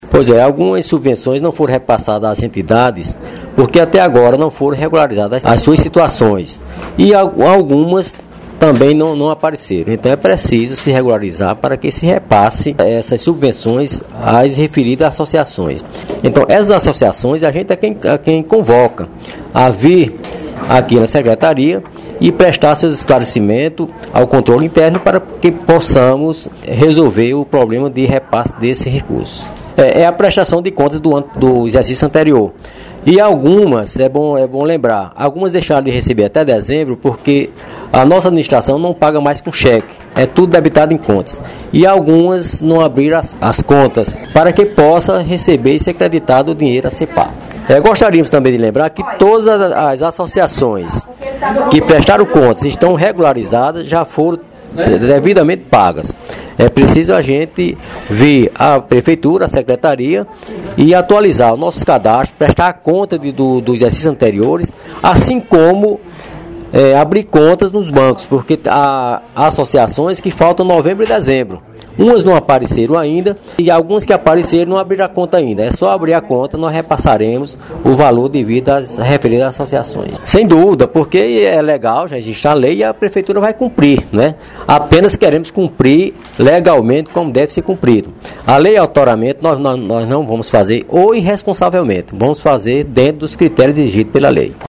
Fala do prefeito em exercício, Bonifácio Rocha –